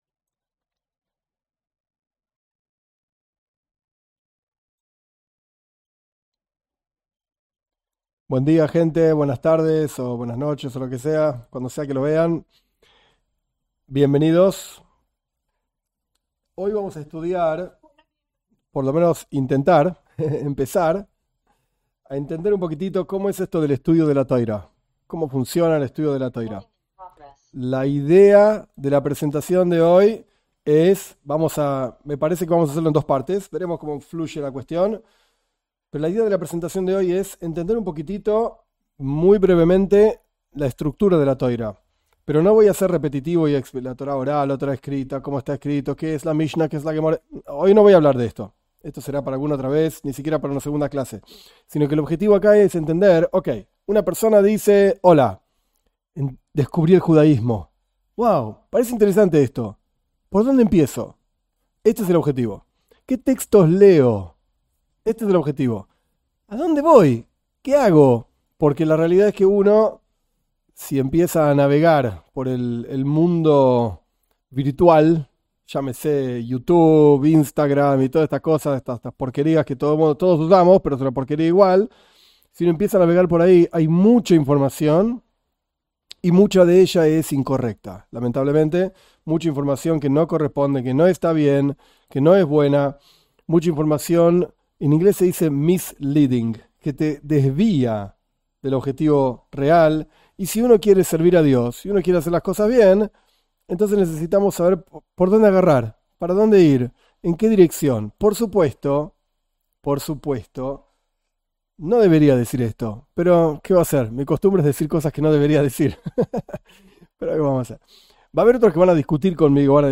En esta clase se explica cómo se estudia Torá. Desde el comienzo hasta la profundización y se explican los libros básicos (y avanzados) que pueden ayudar a abordar el estudio.